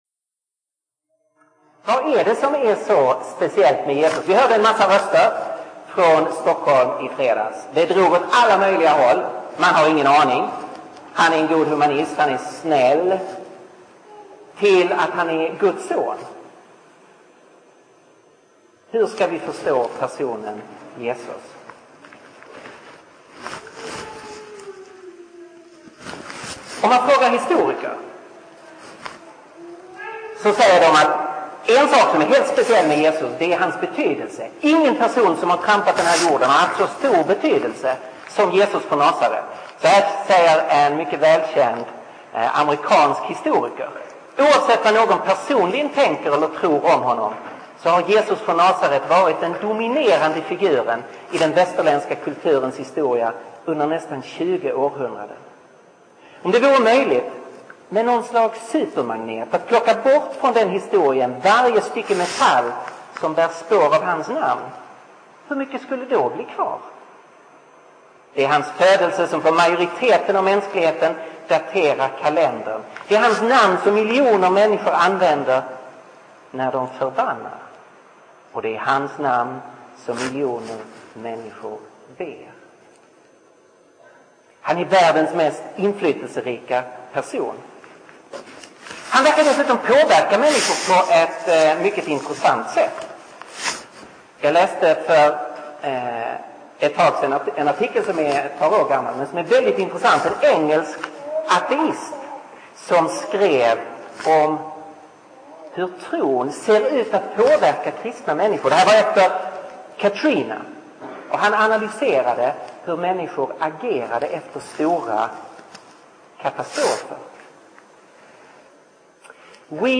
Inspelad vid gudstjänst i BK3 i Betlehemskyrkan i Stockholm 2007-08-26.